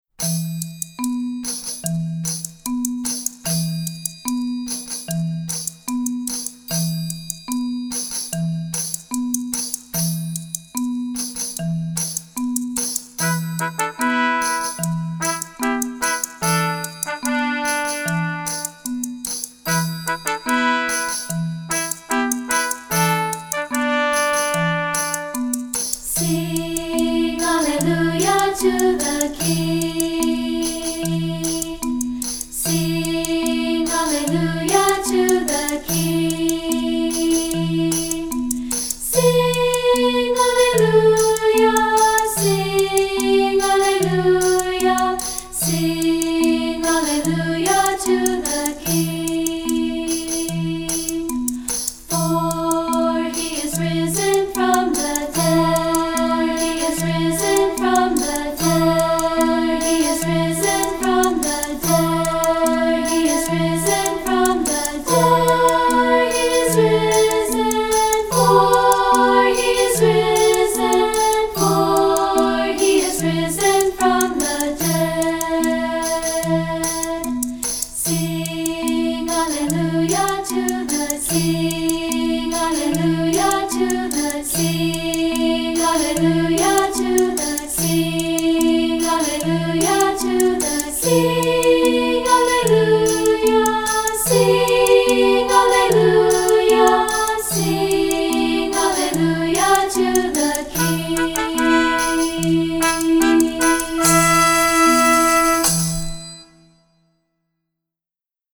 Anthems for Treble Voices
Unison/two-part with Orff instruments and percussion